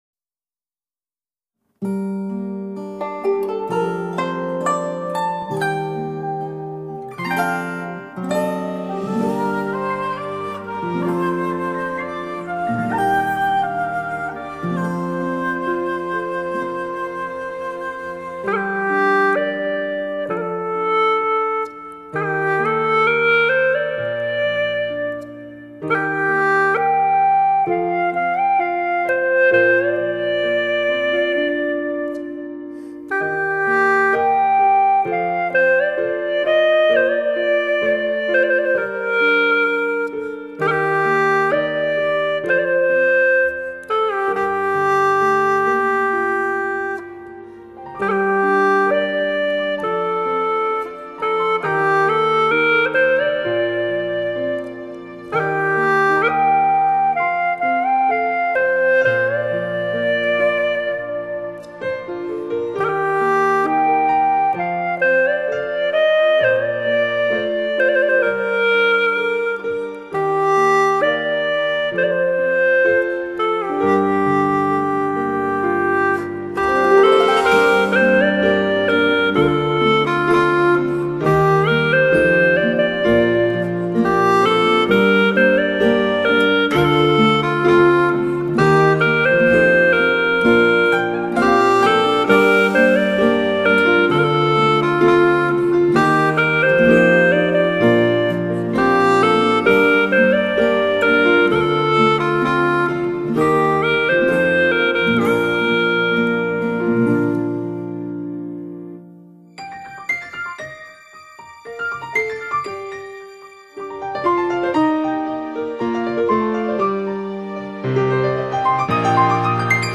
葫芦丝的精美演绎与最新电影歌曲
精美的现代配器与电声乐器的完美融入